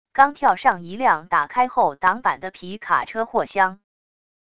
刚跳上一辆打开后挡板的皮卡车货厢wav音频